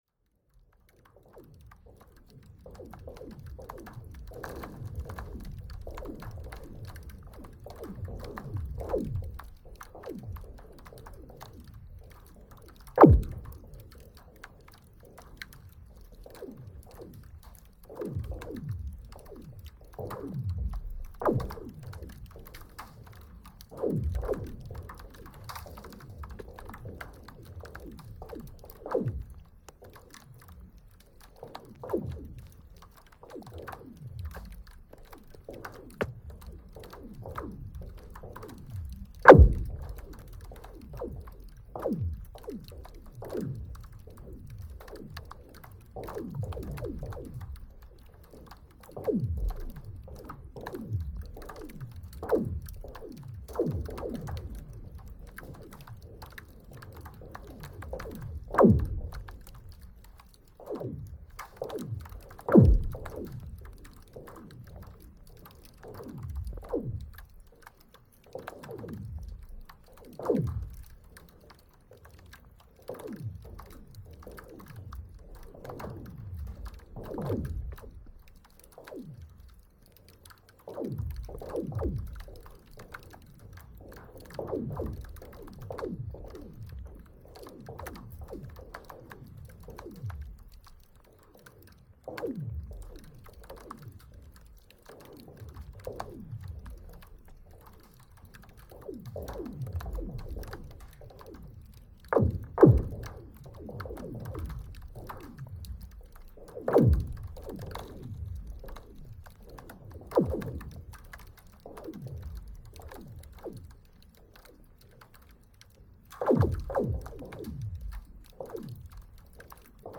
Frozen lake 5th of January 2025
But this cold weather has offered the possibility of recording under frozen lakes near the capital.
I can get very different recordings by finding the right hydrophone location, such as depth and the gap between two hydrophones. Then the frost must be below -5°C and the frosty days must have lasted for more than 5 days.